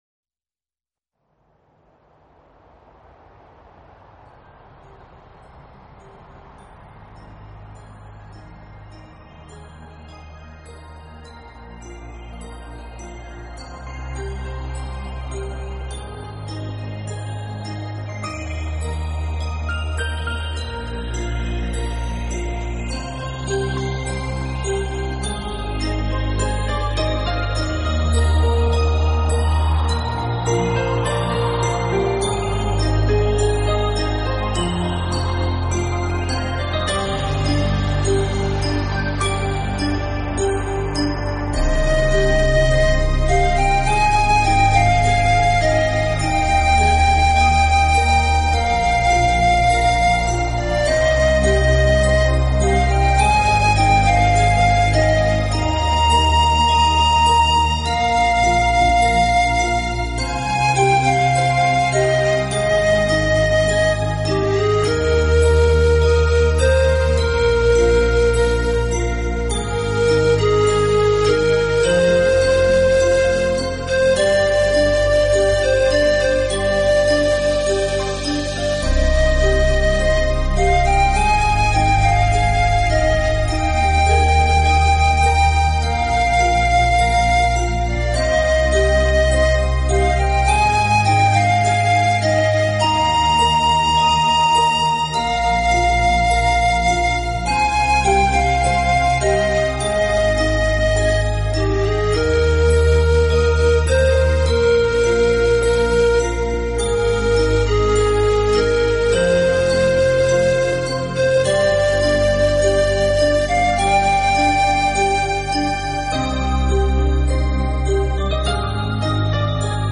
Style: Meditative, Relax